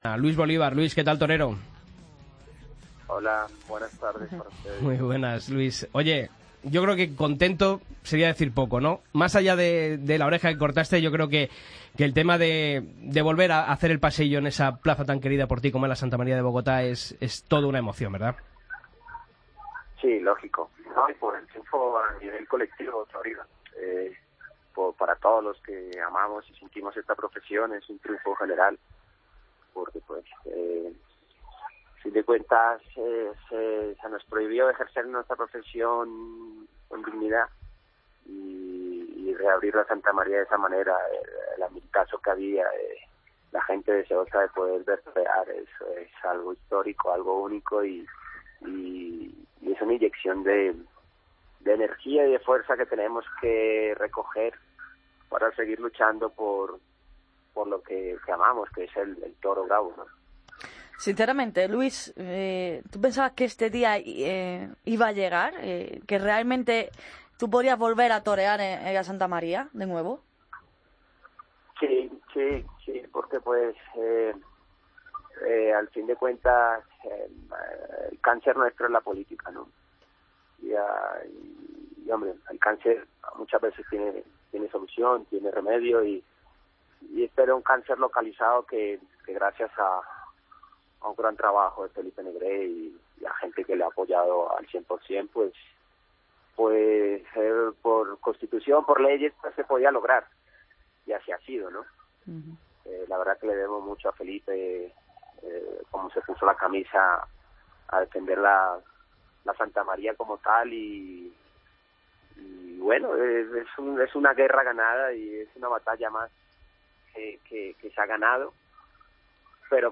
AUDIO: Escucha la entrevista a Luis Bolívar en El Albero